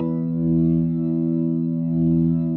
B3LESLIE E 3.wav